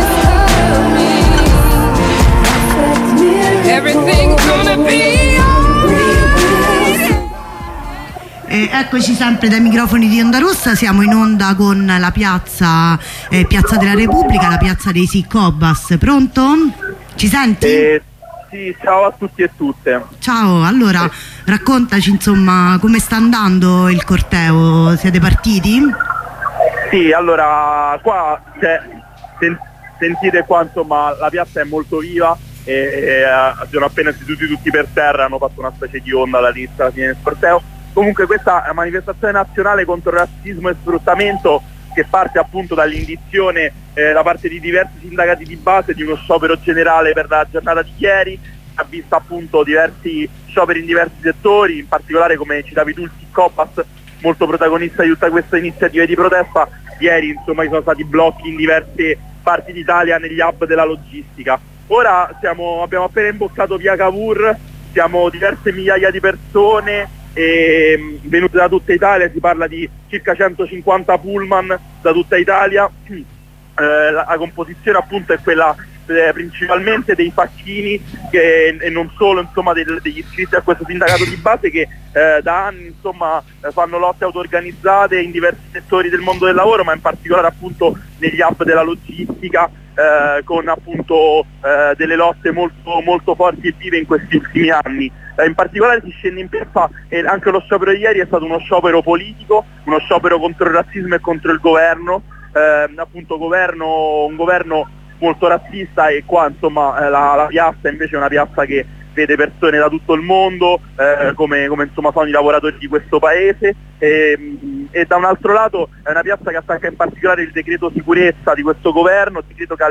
Corrispondenza da Verona: la compagna ci racconta l'apertura del corteo